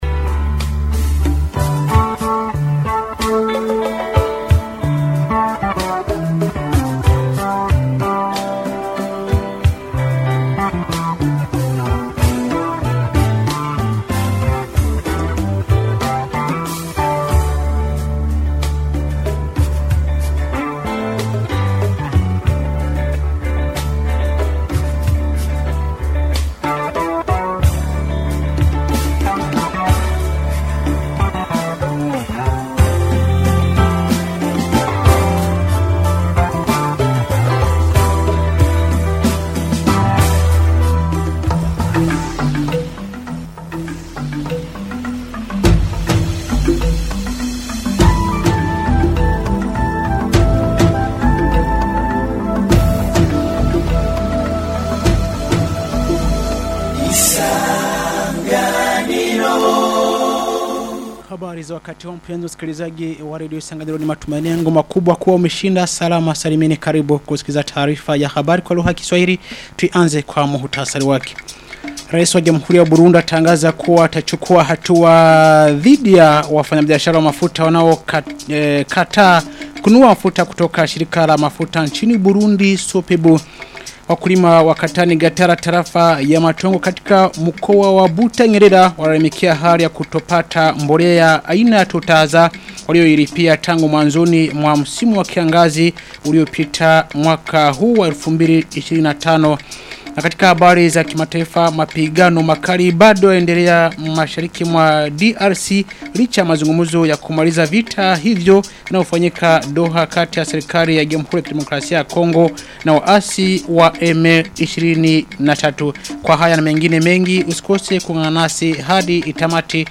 Taarifa ya habari ya tarehe 24 Oktoba 2025